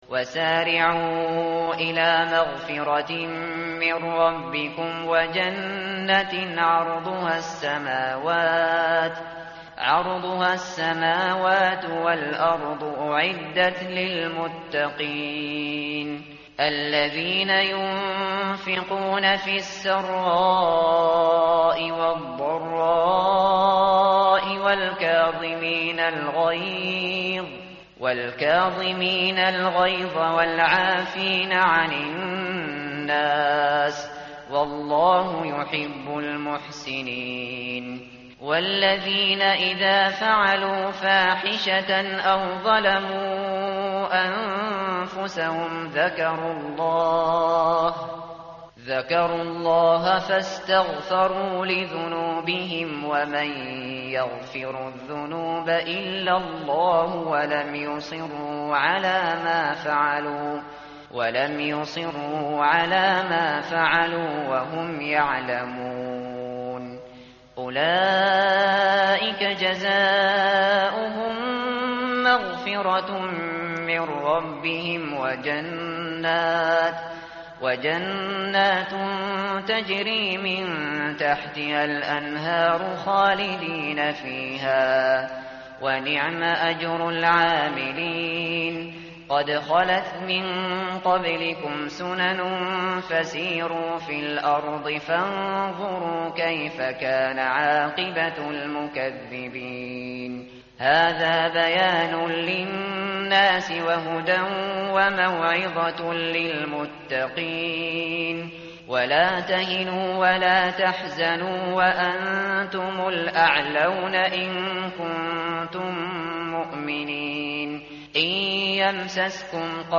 tartil_shateri_page_067.mp3